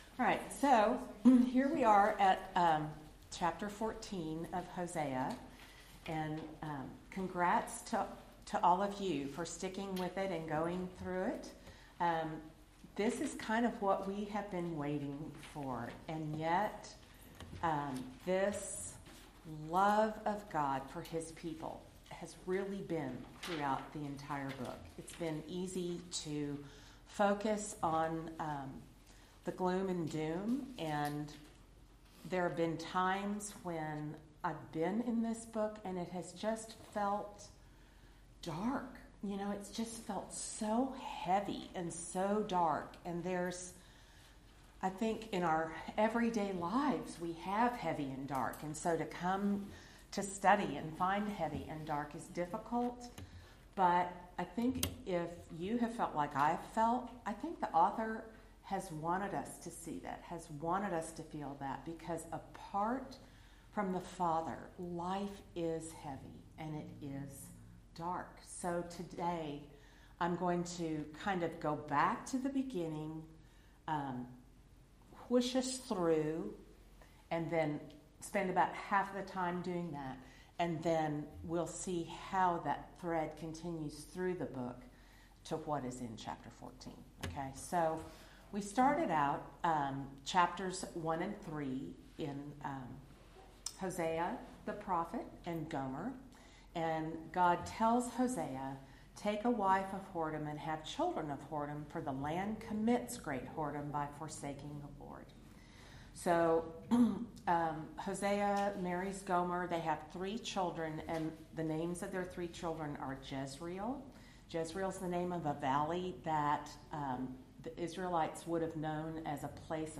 Service Type: Lectures